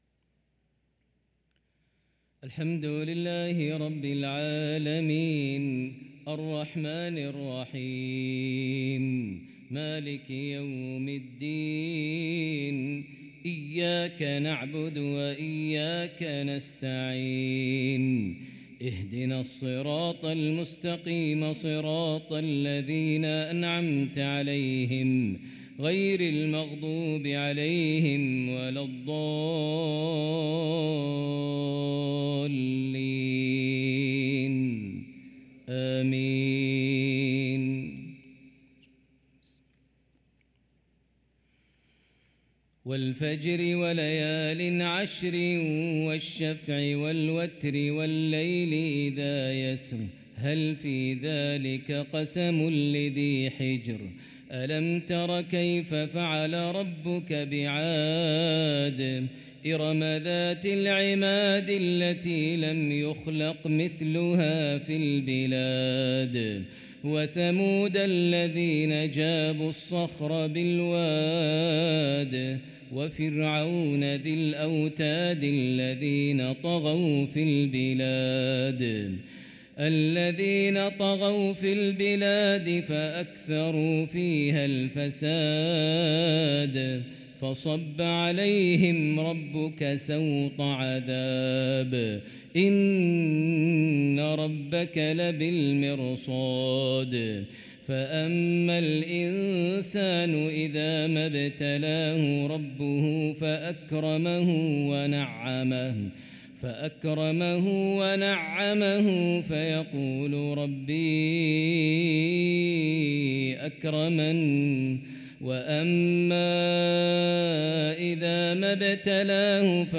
صلاة المغرب للقارئ ماهر المعيقلي 18 ربيع الآخر 1445 هـ
تِلَاوَات الْحَرَمَيْن .